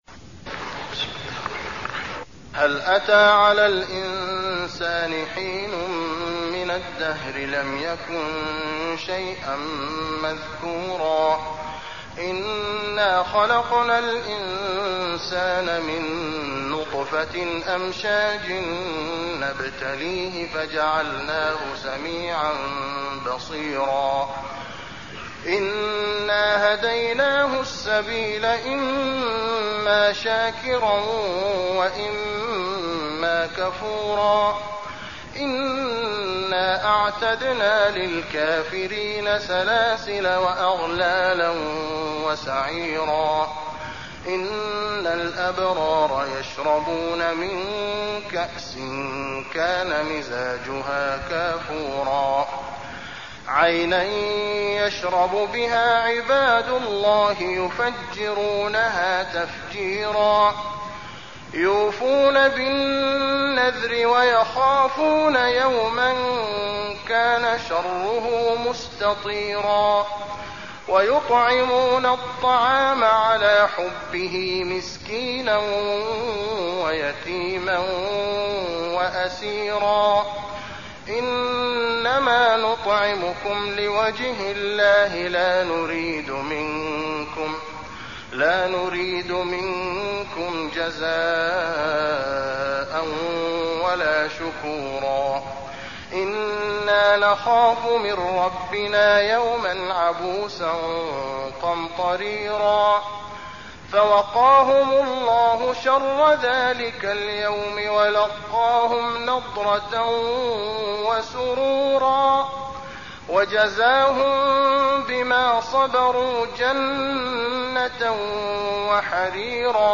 المكان: المسجد النبوي الإنسان